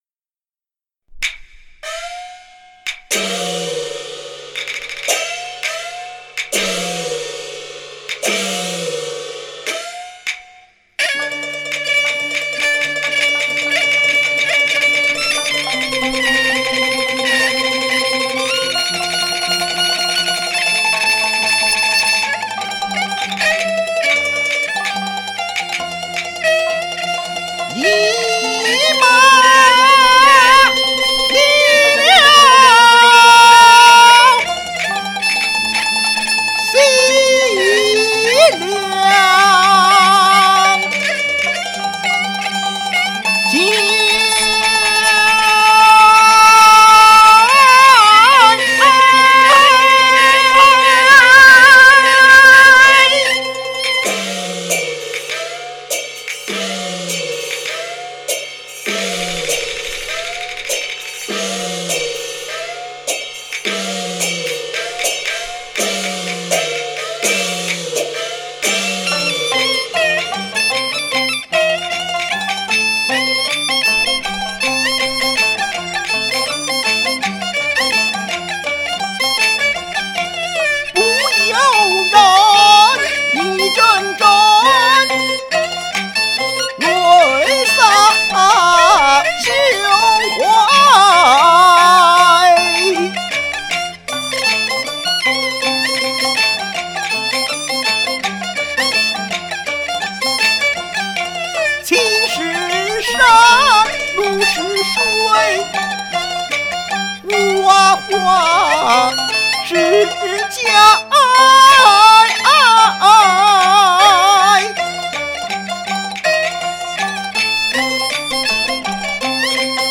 京剧《武家坡》
凭着记忆一唱，没有复习，要是有走样的地方多包涵。